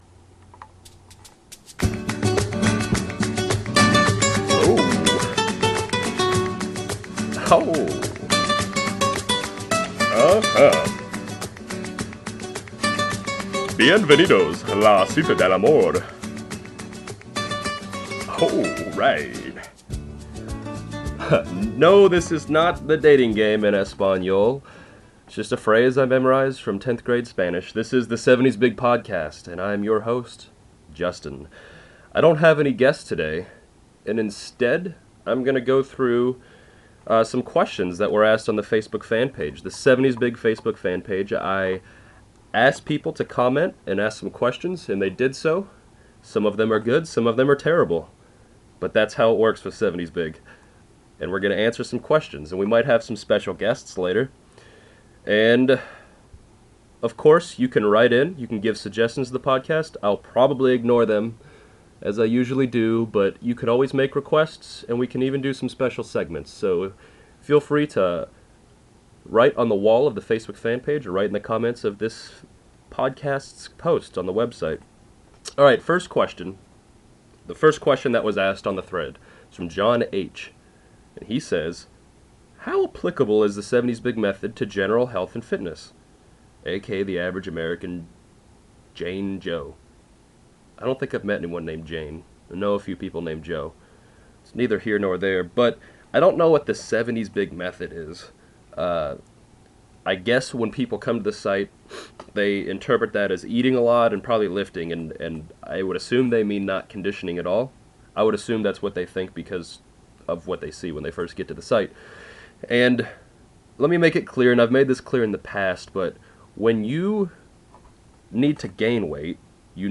This is a solo podcast I did answering both good and horrible questions.
Podcast-5-solo.mp3